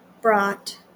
IPA/bɹæt/